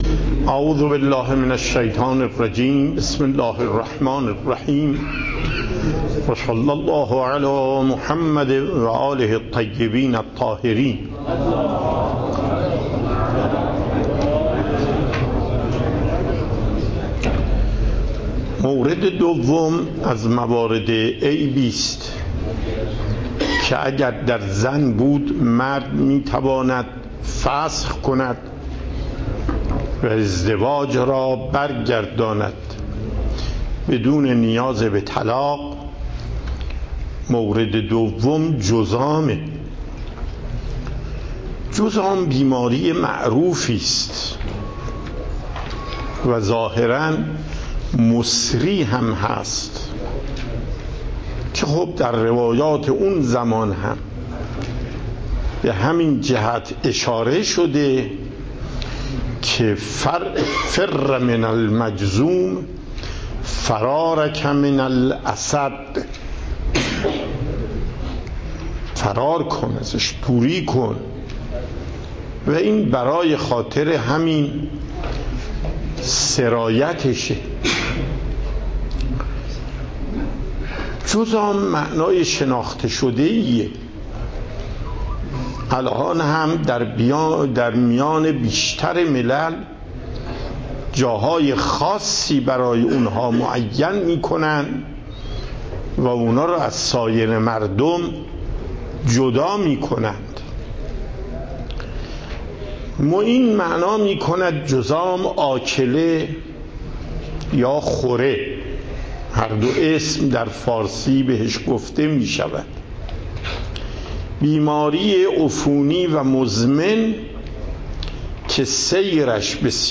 پخش زنده صوت درس + دریافت صوت و تقریر درس